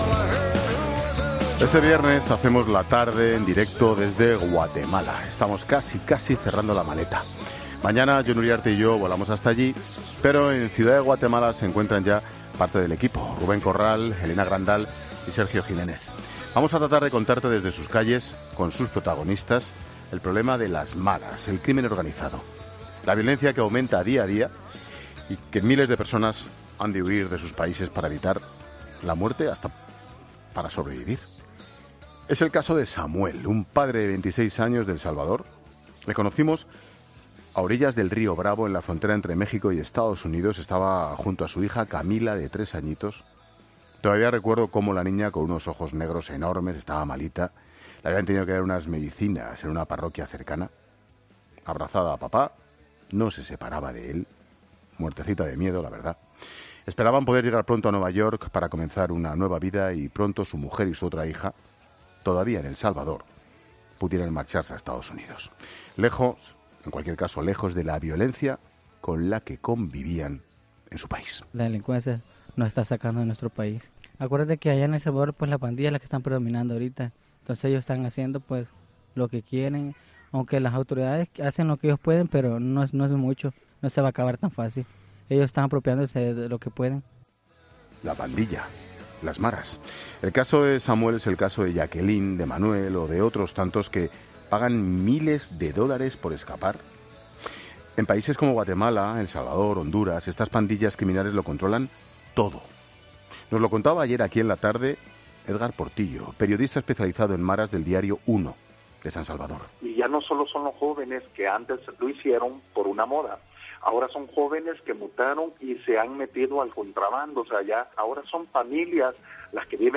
Hablamos con el equipo de 'La Tarde' que ya se encuentra en el país centroamericano